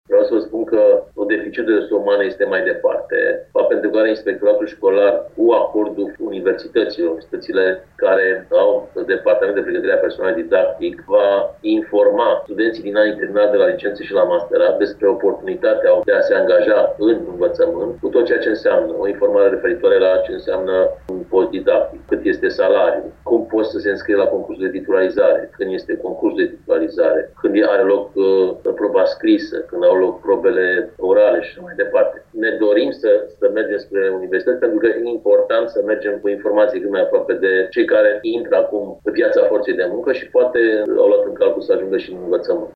Sunt peste 200 de posturi titularizabile, pentru anul şcoalar următor, în Timiş, a anunțat șeful Inspectoratului Școlar din județ, Marin Popescu.